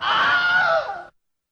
scream_0.wav